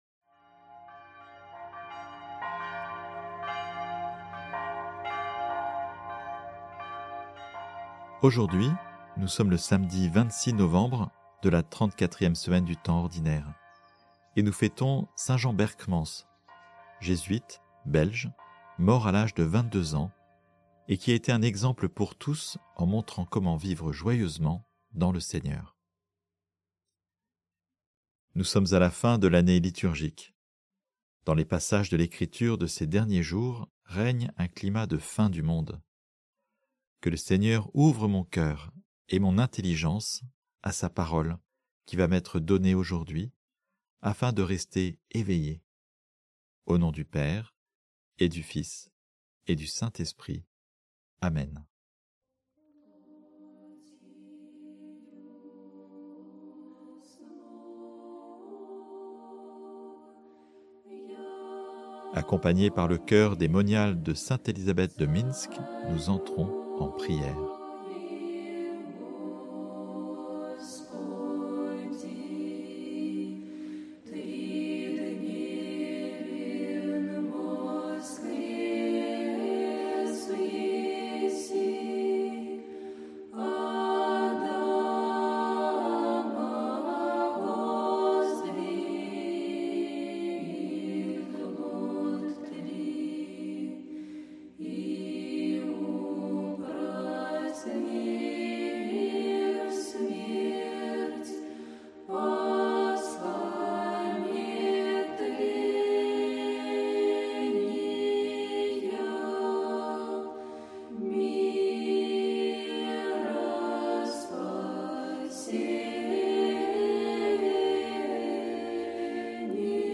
Musiques